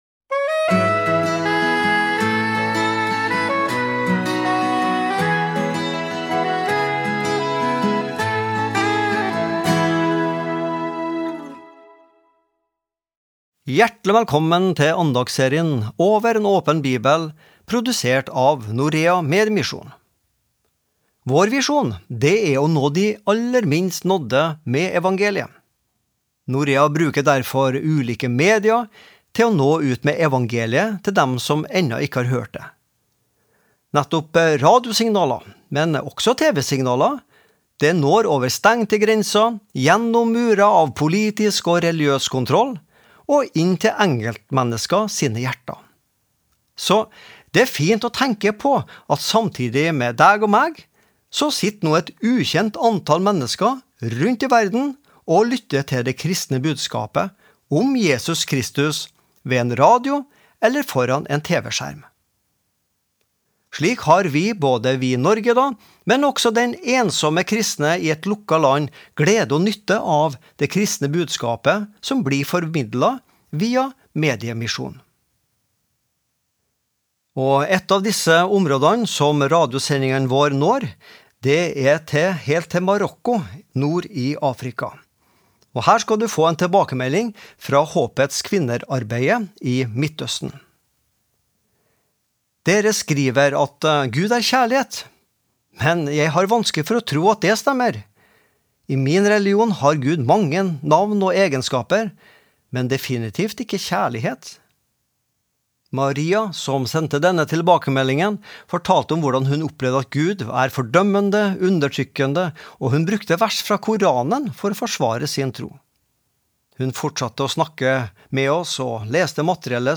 Daglige andakter (mand.-fred.). Forskjellige andaktsholdere har en uke hver, der tema kan variere.